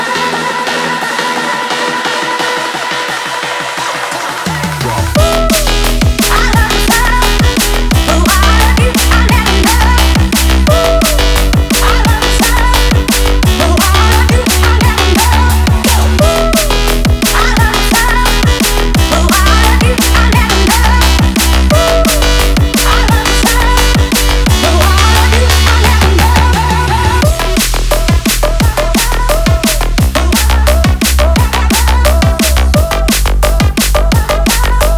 Jungle Drum'n'bass Dance
Жанр: Танцевальные